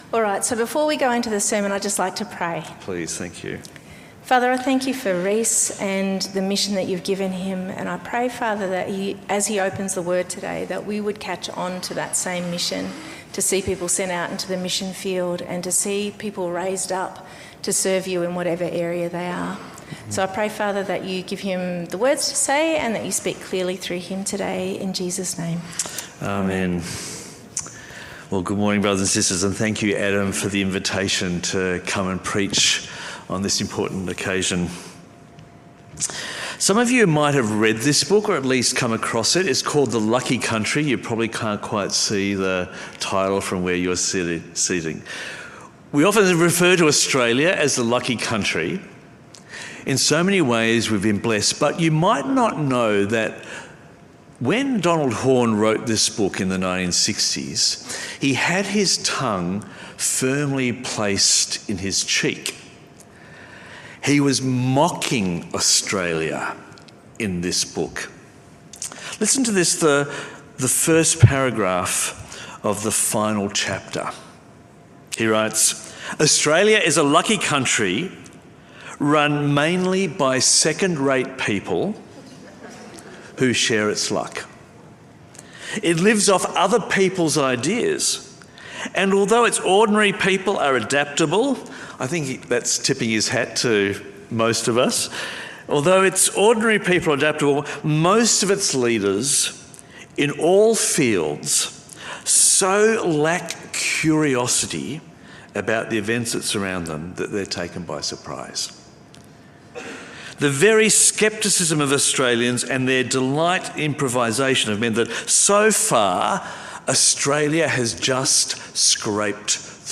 Sermon
Guest Speaker